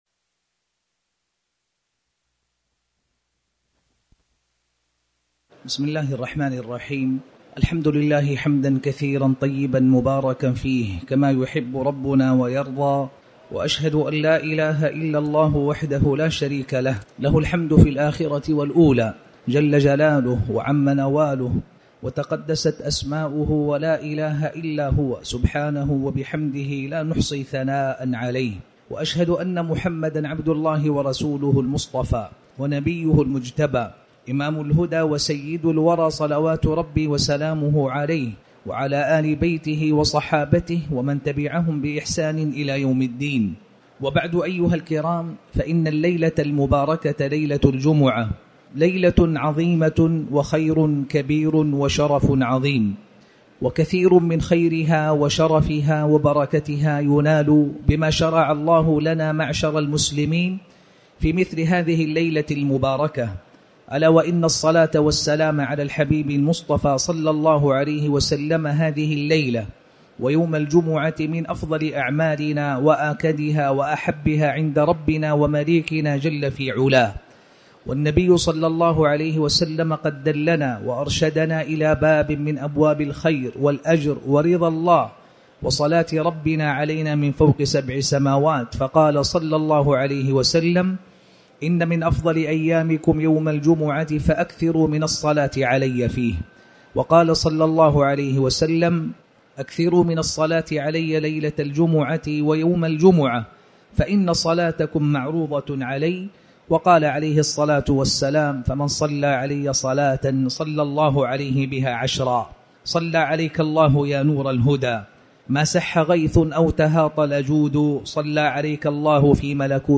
تاريخ النشر ١٦ جمادى الآخرة ١٤٤٠ هـ المكان: المسجد الحرام الشيخ